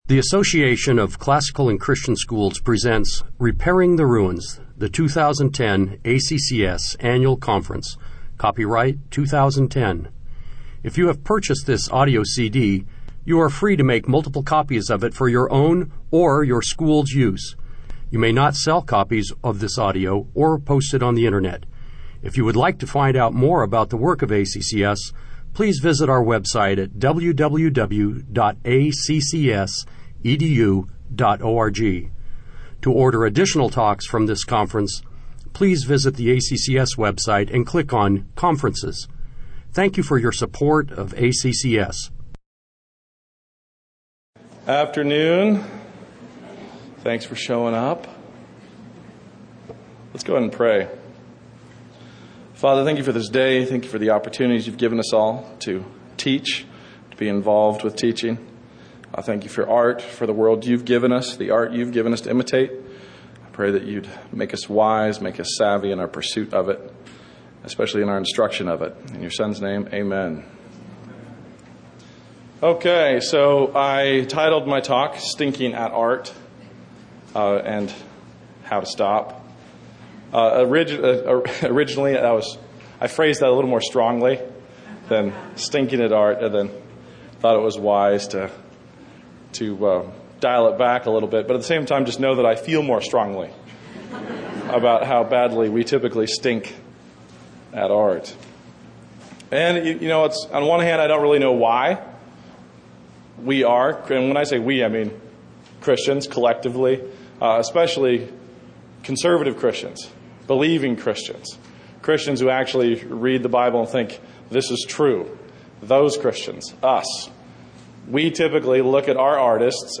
2010 Workshop Talk | 0:56:33 | All Grade Levels, Art & Music
Additional Materials The Association of Classical & Christian Schools presents Repairing the Ruins, the ACCS annual conference, copyright ACCS.